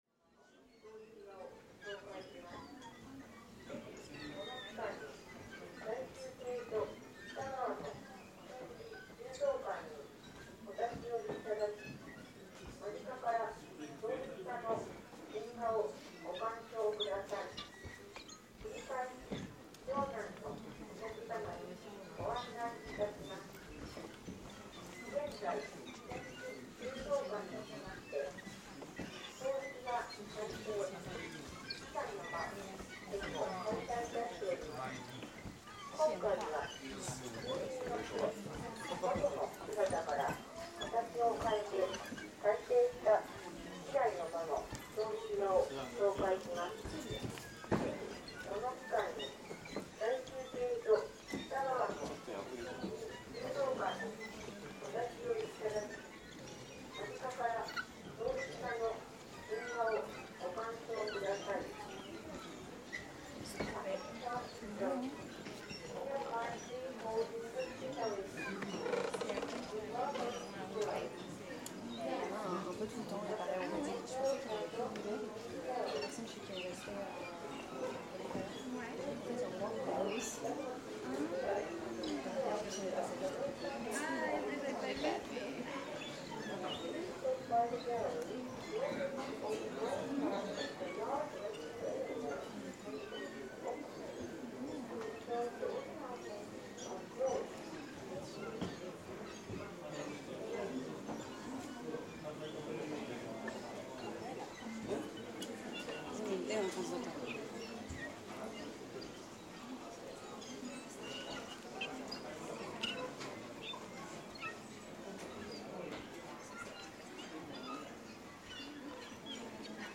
This recording was taken inside Nijo castle, one of Kyoto's Cultural World Heritage site, which features a 'nightingale floor': the wooden floor's nails are positioned in such a way that they rub against a jacket or clamp, and overtime produce squeaking or chirping noises when walked on, sounding almost like the bird it is named after. Legend has it these floors were installed as an analog security system to ward off intruders, but it seems that the effect initially arose by chance. You can hear these floorboards singing in the recording, along with the sounds of the flow of tourists that make them sing. In the first half an anouncement in Japanese (and then English) can be heard, and the overall atmosphere gradually changes as we move through the building. Some handling noise can be heard too (recorded with a phone handheld).